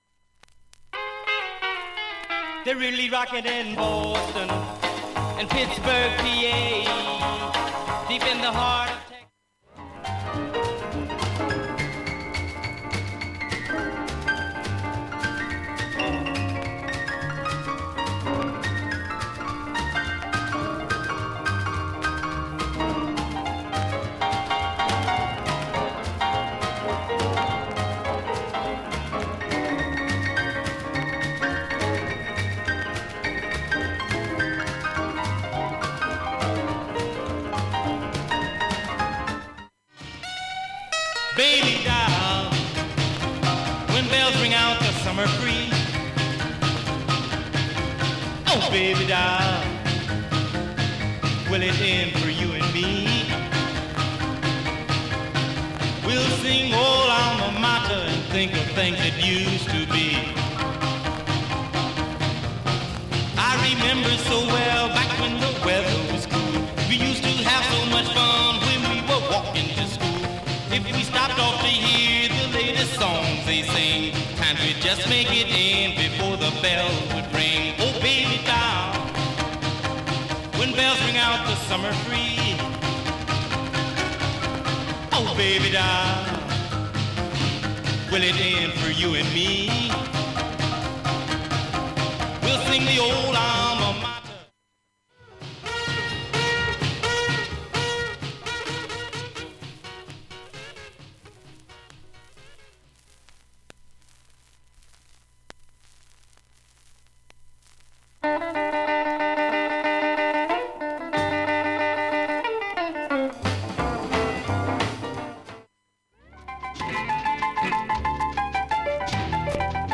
プツプツ、チリプツ出ます。
◆ＵＳＡ盤オリジナル Mono